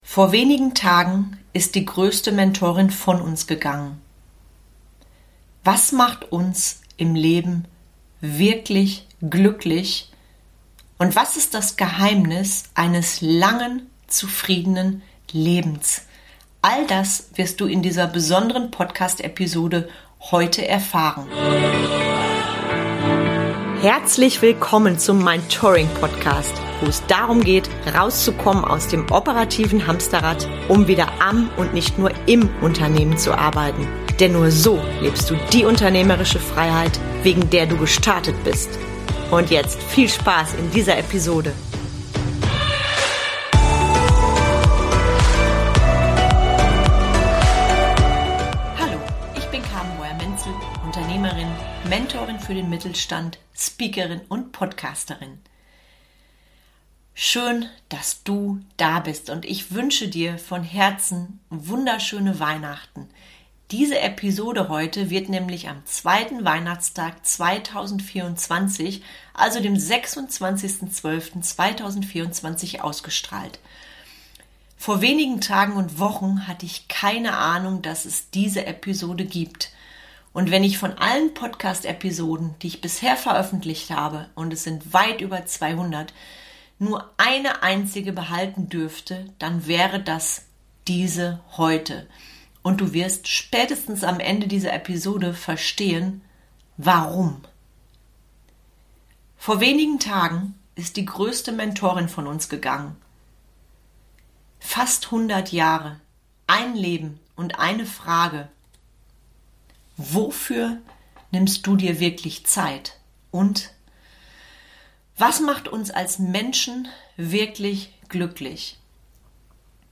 Wir sprachen über richtige Entscheidungen und darüber, was am Ende bleibt. Dieses Interview möchte ich heute an Zweitweihnachten nochmals mit dir teilen.